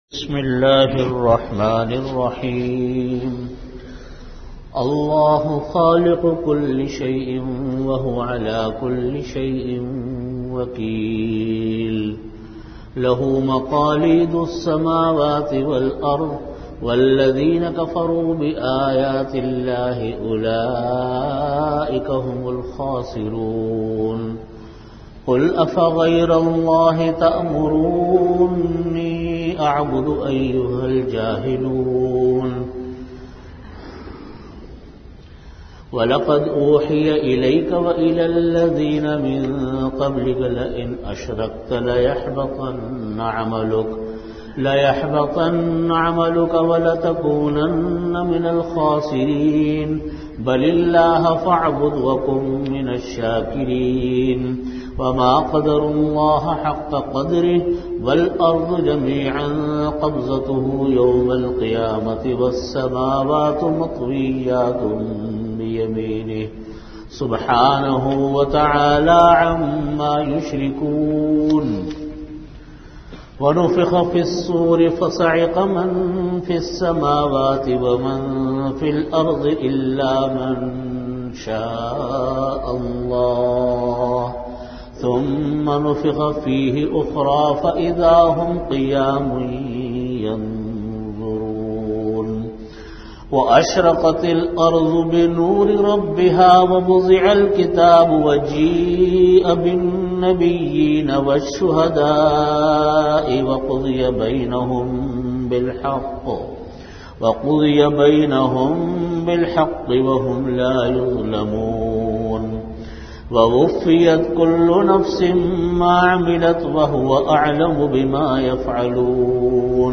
Category: Tafseer
Venue: Jamia Masjid Bait-ul-Mukkaram, Karachi